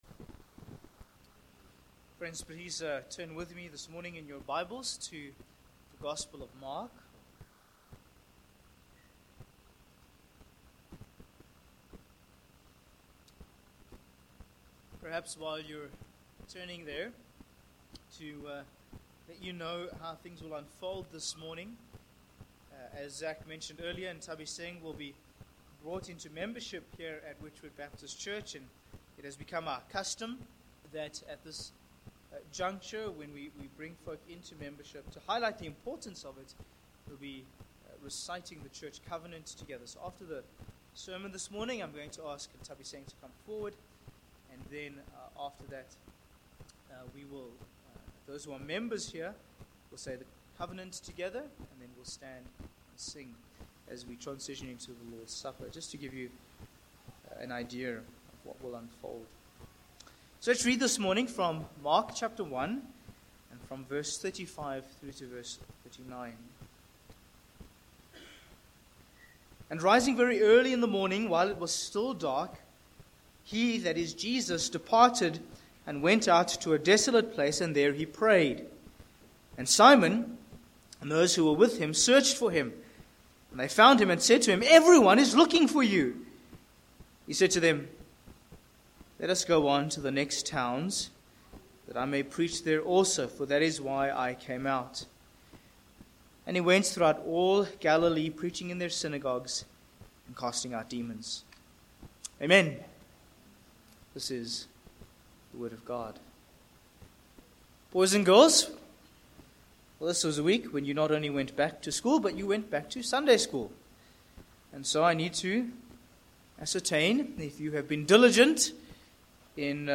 Service Type: Morning
Sermon